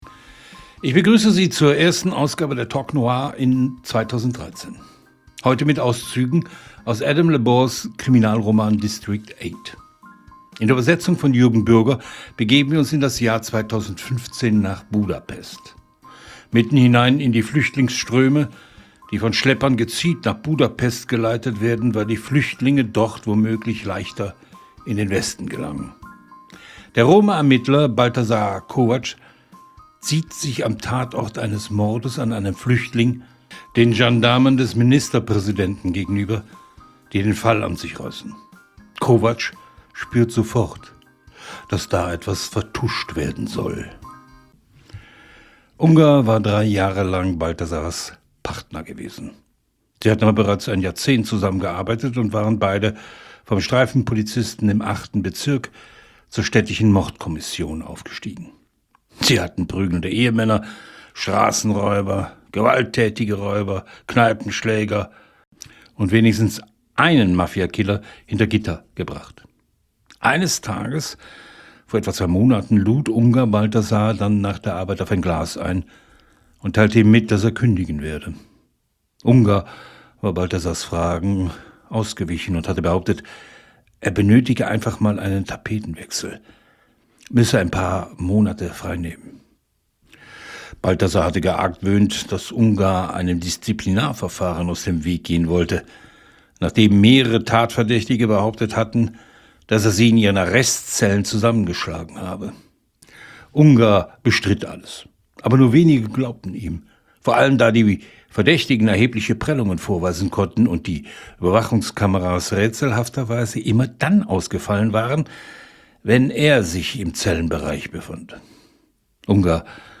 Wie bei den Talk-Noir-Veranstaltungen in Bremen und Berlin sitzen wir zu Dritt am Mikrofon und reden über das Genre, seine Autorinnen und Autoren abseits des Mainstreams.